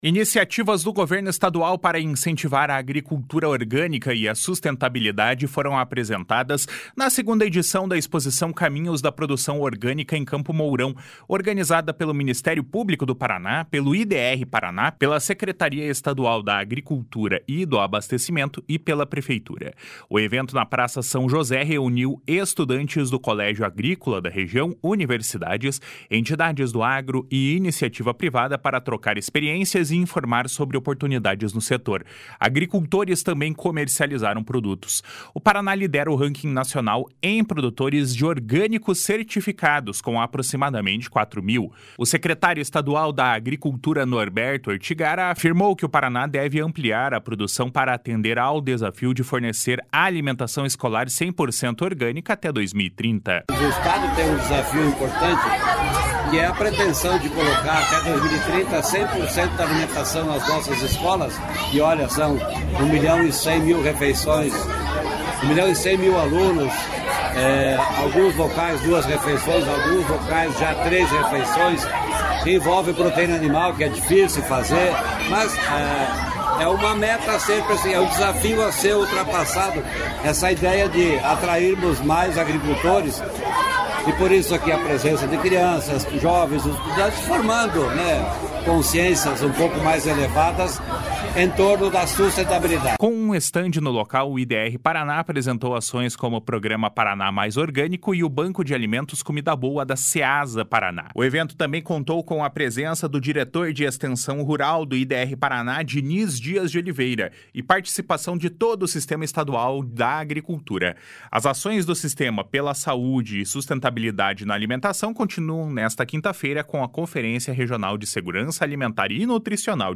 O secretário estadual da Agricultura e do Abastecimento, Norberto Ortigara, afirmou que o Estado deve ampliar a produção para atender ao desafio de fornecer alimentação escolar 100% orgânica até 2030. // SONORA NORBERTO ORTIGARA //